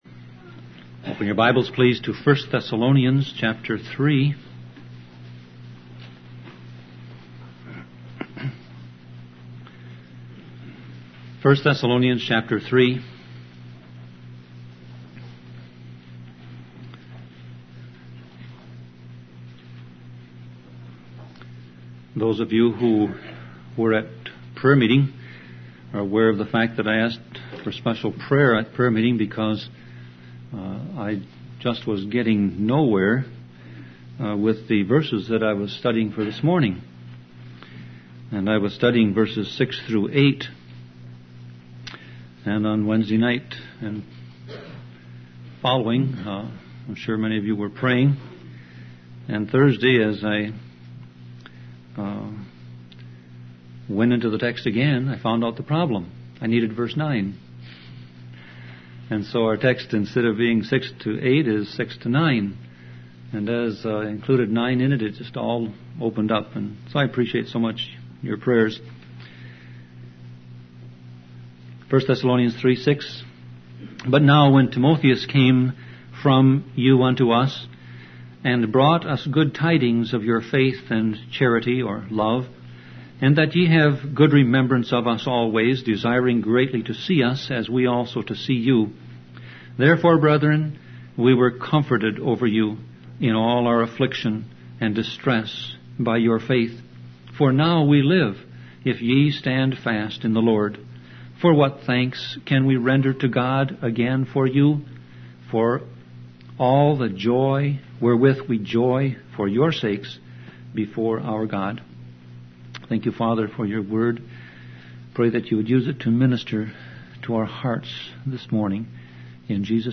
Series: Sermon Audio Passage: 1 Thessalonians 3:6-9 Service Type